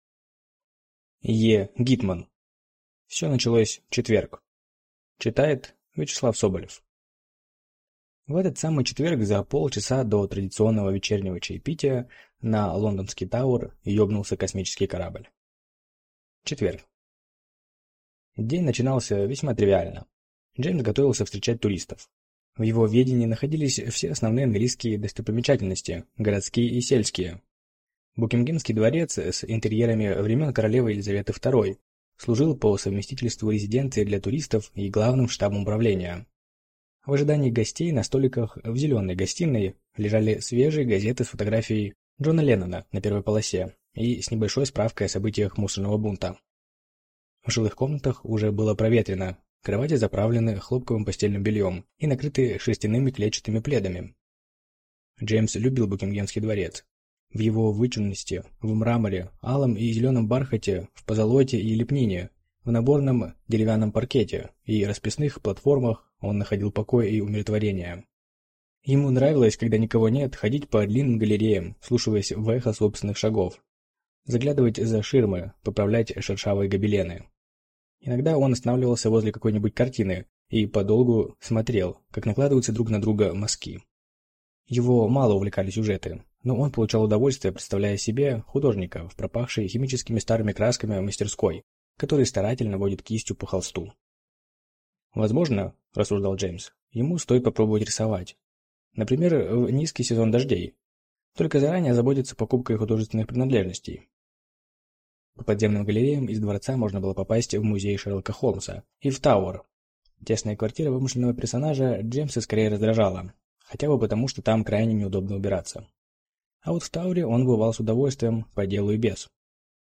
Аудиокнига Всё началось в четверг | Библиотека аудиокниг